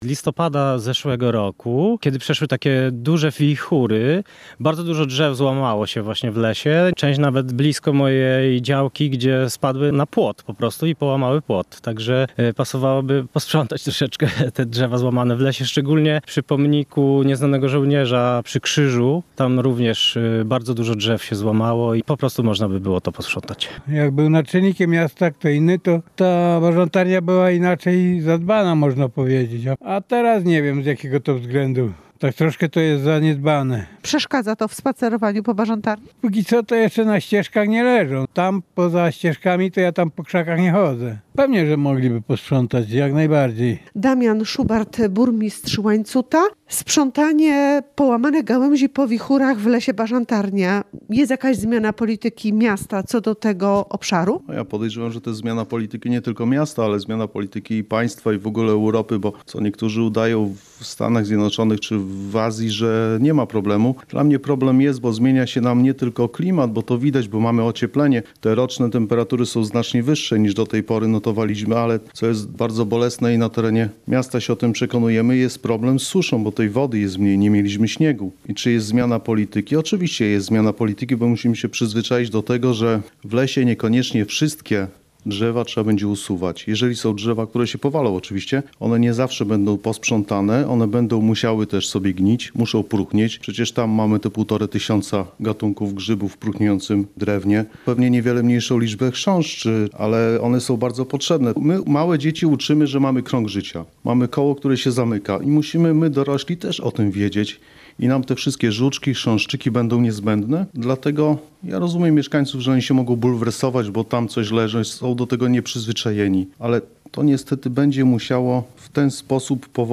Niektórym mieszkańcom Łańcuta nie podoba się to, że w miejskim lasku Bażantarnia, leży sporo połamanych gałęzi. Dzieje się tak od kilku miesięcy, po jesiennych wichurach – opowiada jeden z mieszkańców.
Burmistrz Łańcuta, Damian Szubart odpowiada, że zmiana w podejściu do sprzątania lasku, wynika ze zmian klimatu.